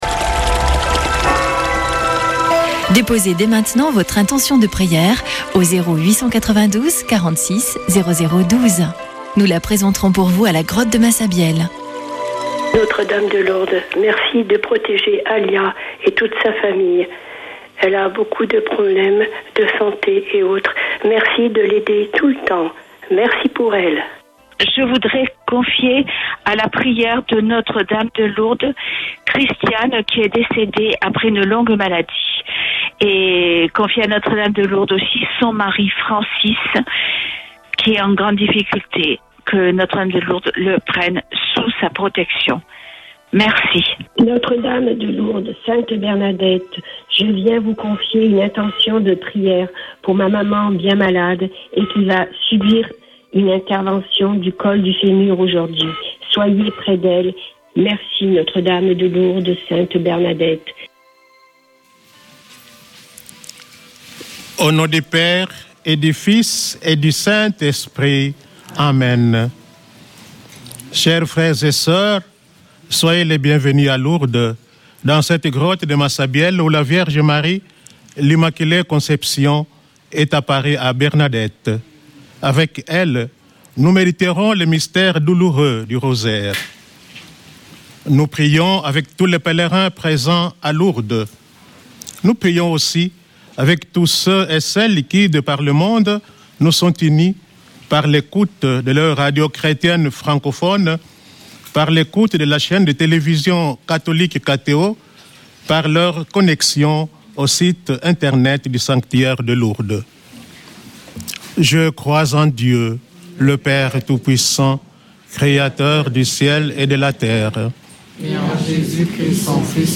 Accueil \ Emissions \ Foi \ Prière et Célébration \ Chapelet de Lourdes \ Chapelet de Lourdes du 13 févr.
Une émission présentée par Chapelains de Lourdes